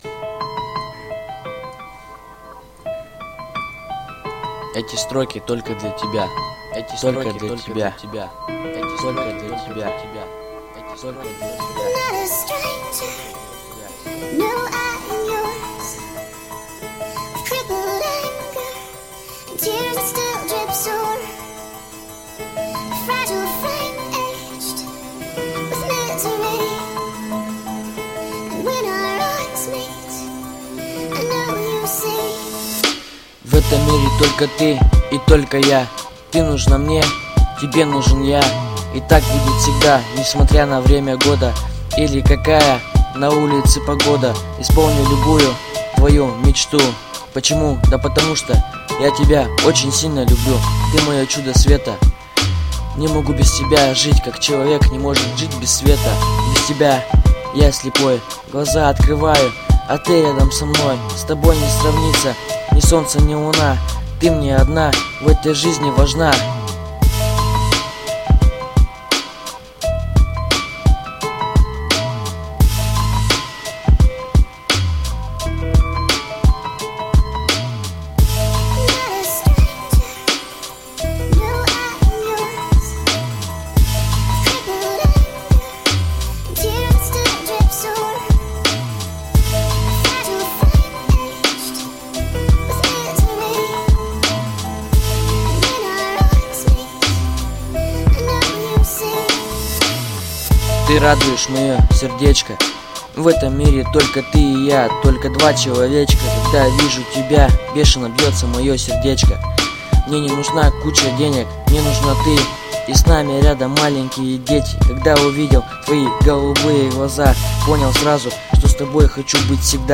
Любовный рэп